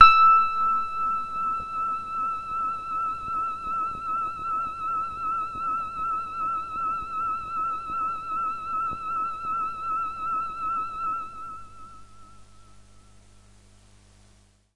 描述：这是我的Q Rack硬件合成器的一个样本。
低通滤波器使声音变得圆润而柔和。
在较高的区域，声音变得非常柔和，在归一化之后，一些噪音变得很明显。
用Waldorf Q Rack合成器创作，通过我的Spirit 328数字控制台在Cubase 4中以32位波形文件进行数字录制。
Tag: 电子 醇厚 多样品 合成器 低音 华尔